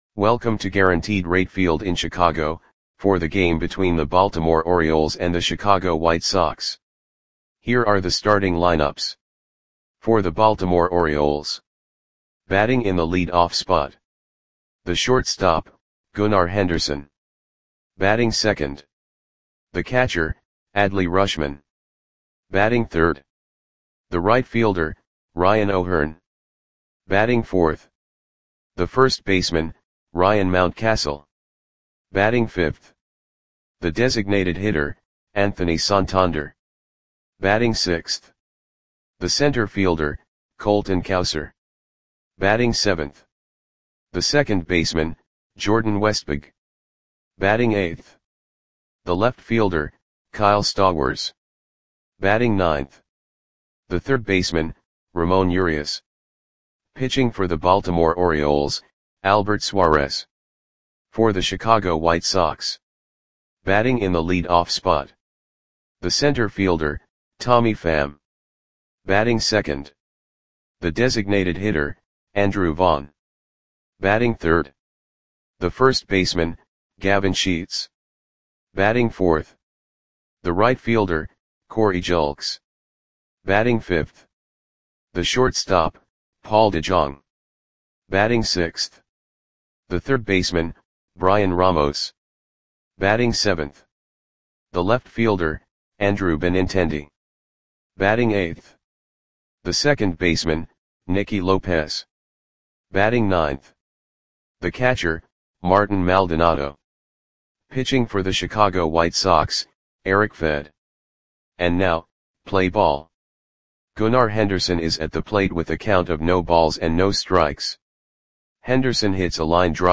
Audio Play-by-Play for Chicago White Sox on May 25, 2024
Click the button below to listen to the audio play-by-play.